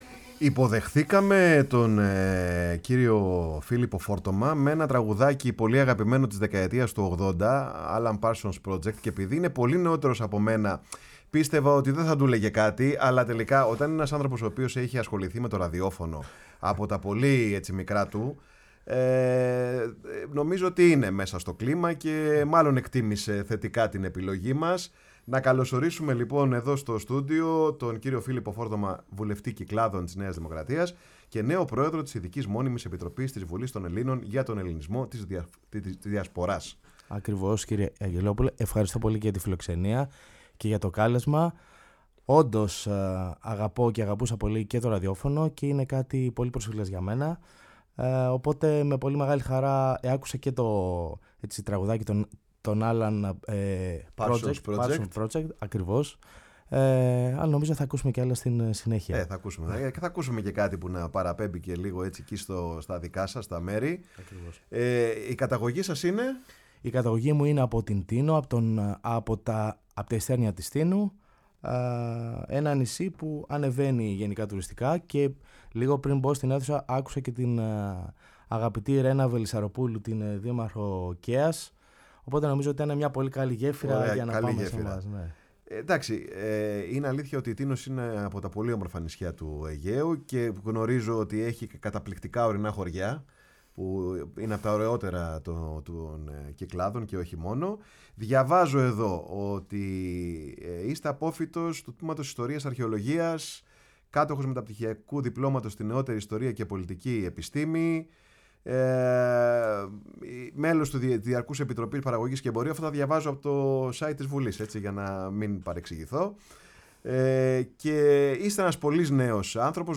Ο Φίλιππος Φόρτωμας, βουλευτής της ΝΔ και Πρόεδρος της Ειδικής Μόνιμης Επιτροπής Ελληνισμού της Διασποράς, φιλοξενήθηκε σήμερα Πέμπτη, 22 Φεβρουαρίου, στο στούντιο της “Φωνής της Ελλάδας” και στην εκπομπή ”Πάρε τον Χρόνο σου”